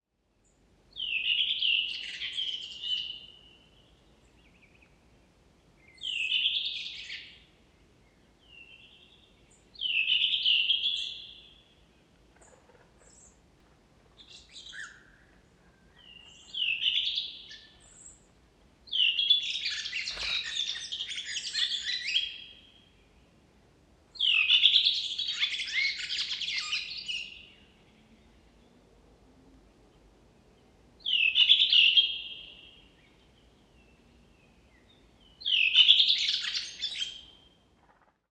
Het geluid van een Koperwiek
• De koperwiek laat een uniek, scherp en hoog geluid horen tijdens de vlucht, te herkennen aan “psrieee…”. Dit helpt ze om elkaar te vinden tijdens de nachtelijke trek van september tot mei.
• Zijn zang bestaat uit op-en-neergaande patronen, vaak beschreven als “turie-turie-turie-turie-tuur…”, die per regio kan variëren. Dit maakt zijn territoriumzang uniek en duidelijk herkenbaar.
Ook de herhalingen van knarsende fluittonen dragen bij aan zijn herkenbaarheid.
Het korte geluid “tsjuk” hoor je als hij zit.